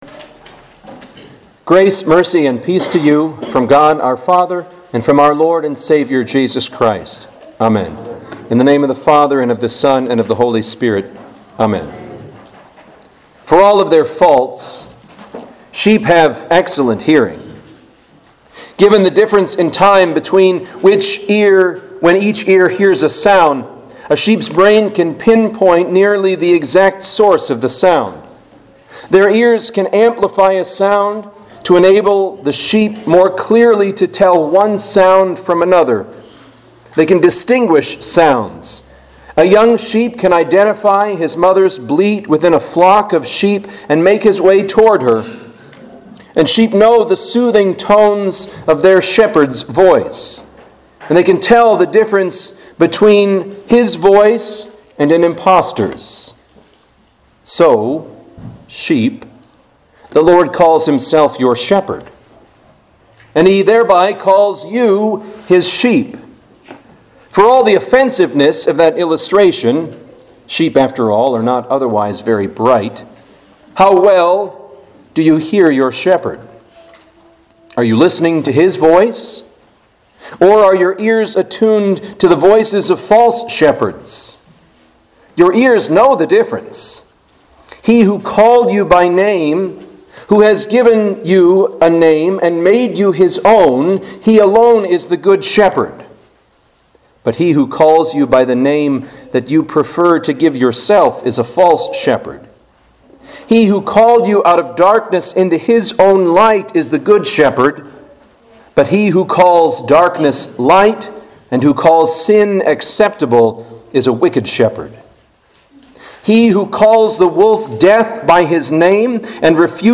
Home › Sermons › Misericordias Domini